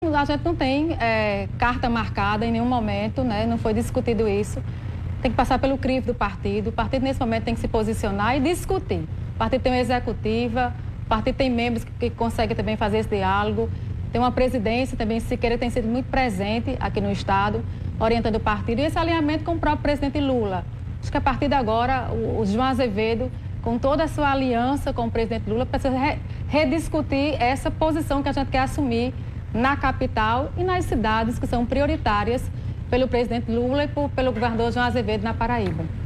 A secretária de Desenvolvimento Humano do Estado, Pollyanna Dutra, não descartou a possibilidade ser candidata a prefeita de João Pessoa nas eleições de 2024. “Não está nos meus planos, mas em política não se pode radicalizar”, disse a secretária durante entrevista a TV Arapuan.